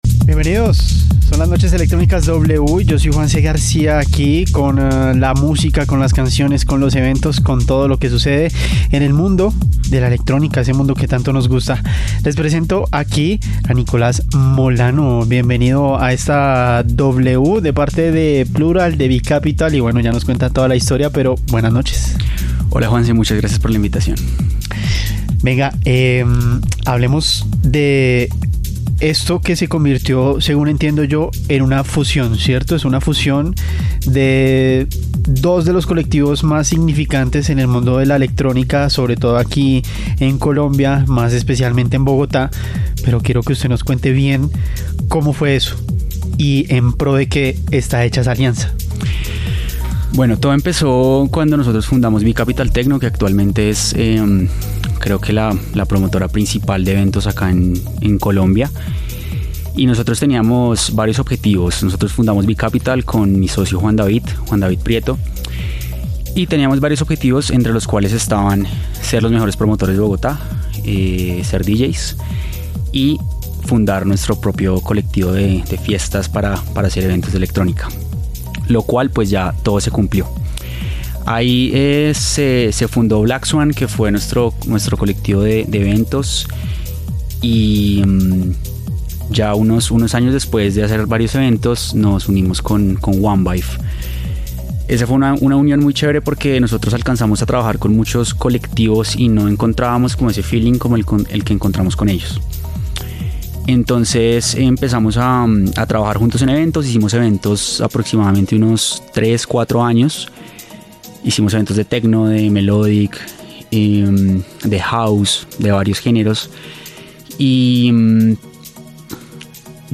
ENTREVISTA PLURAL